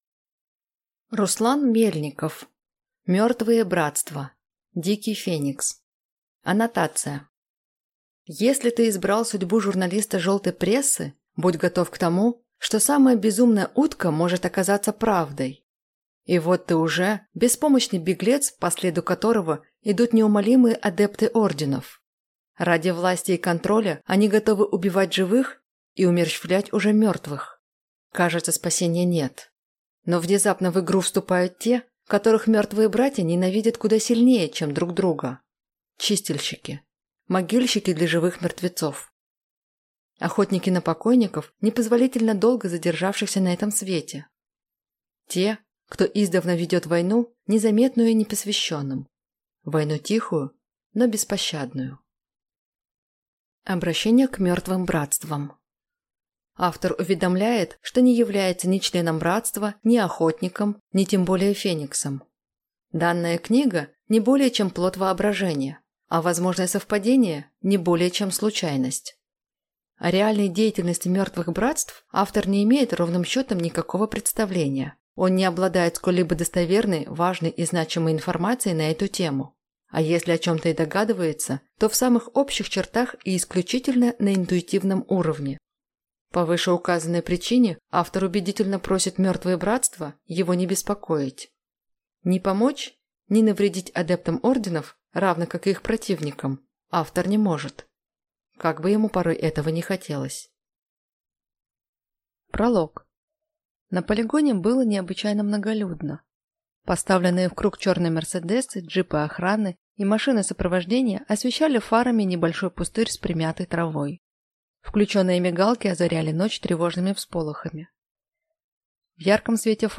Аудиокнига Дикий Феникс | Библиотека аудиокниг